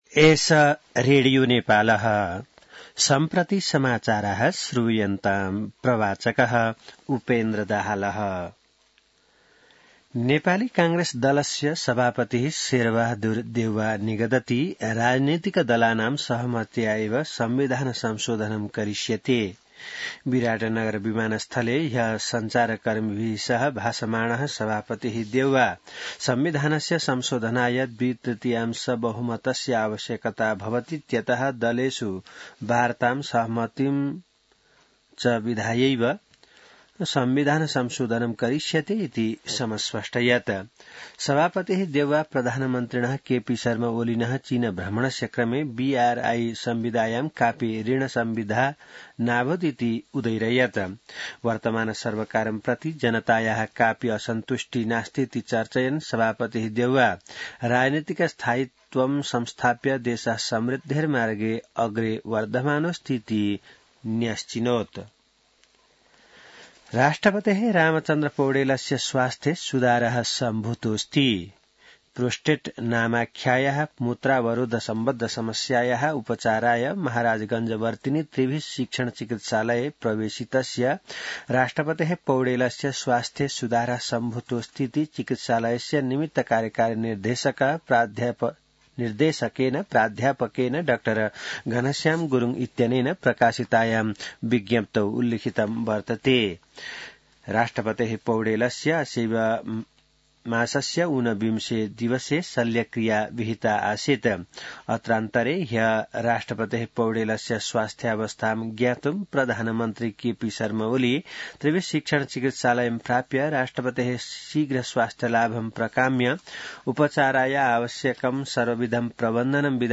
संस्कृत समाचार : २३ मंसिर , २०८१